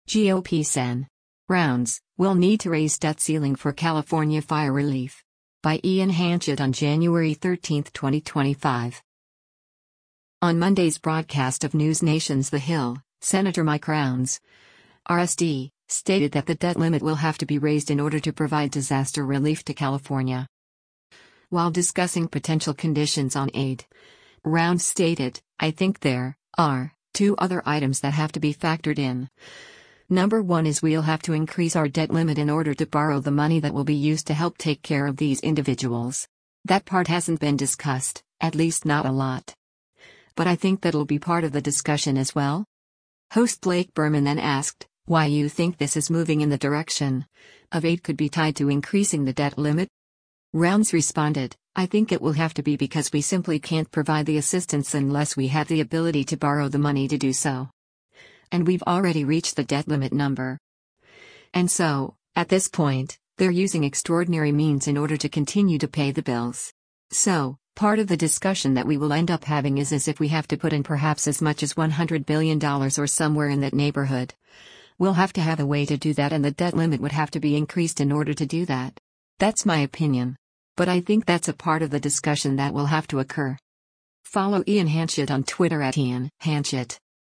On Monday’s broadcast of NewsNation’s “The Hill,” Sen. Mike Rounds (R-SD) stated that the debt limit will have to be raised in order to provide disaster relief to California.